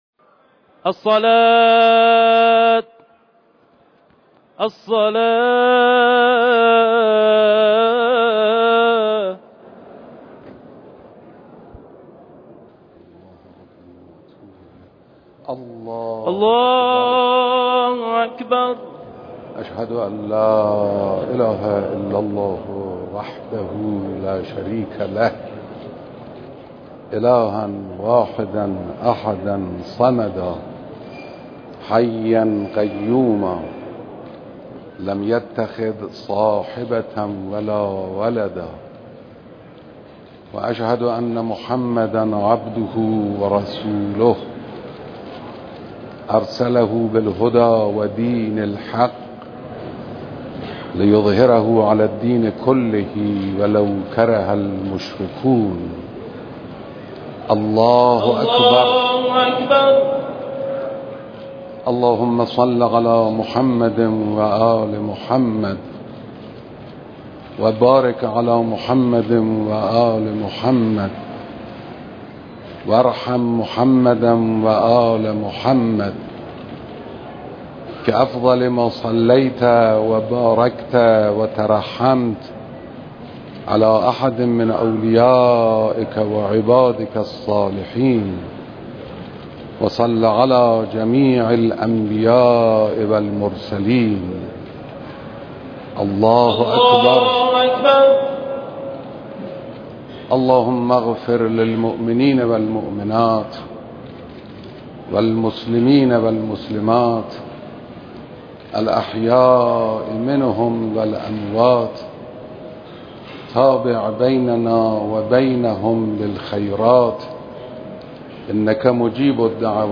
اقامه نماز بر پیکر مطهر آیت الله خوشوقت
رهبر معظم انقلاب اسلامی بر پیکر مطهر آیت الله خوشوقت نماز اقامه کردند